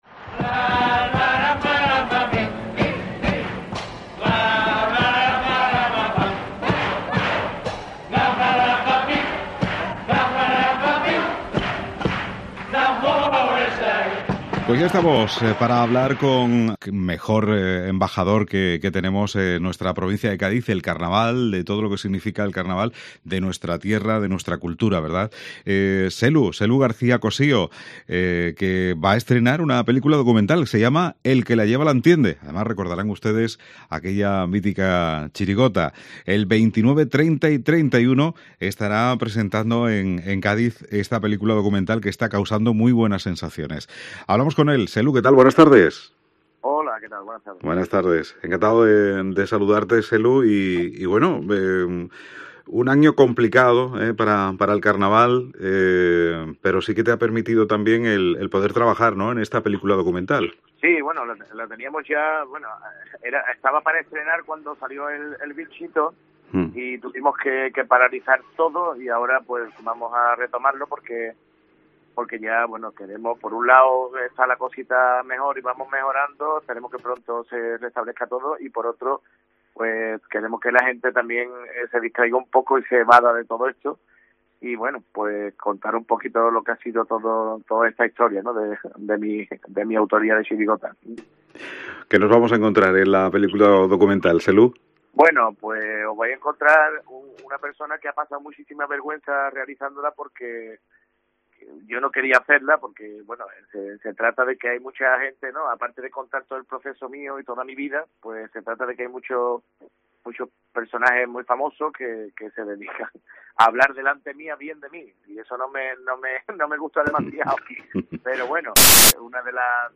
El Selu habla de su documental en los micrófonos de COPE